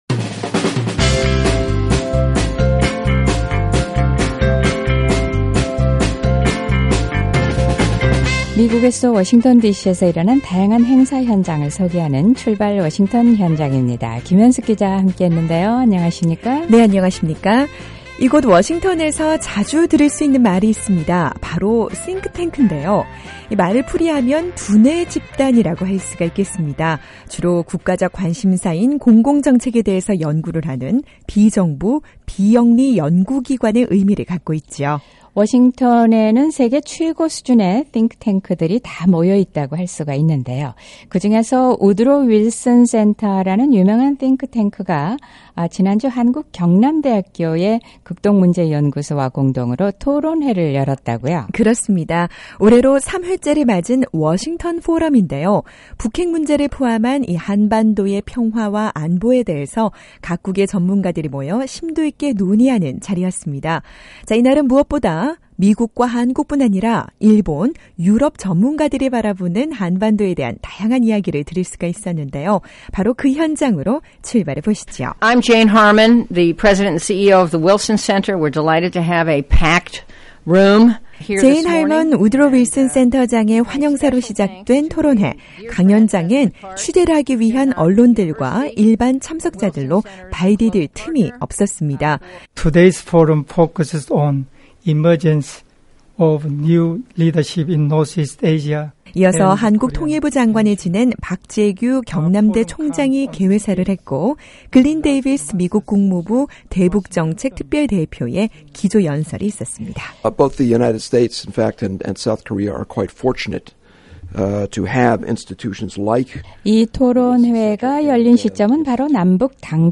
저와 함께 토론회 현장으로 출발해보시죠!